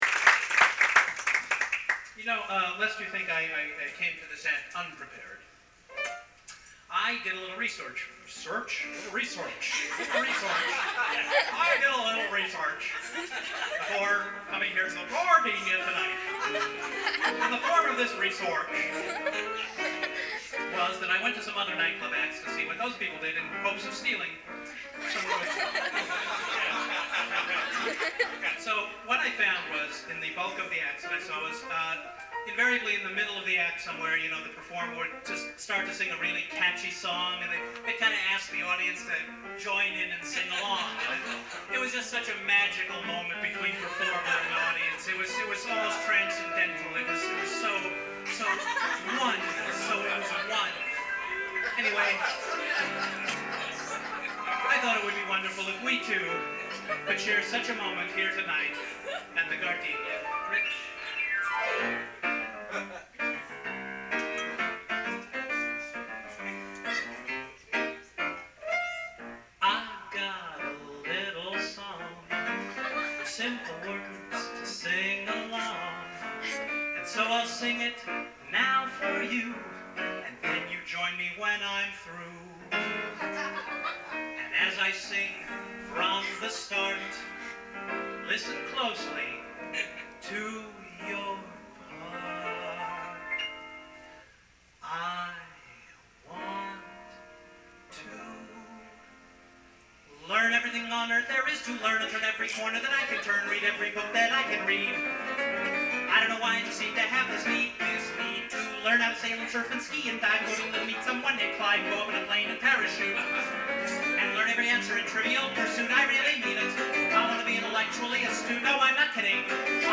I thought it would be fun to write a sing-a-long song that was impossible to sing along to – the quality is very tinny but if you turn your volume up, you’ll be able to hear most of it.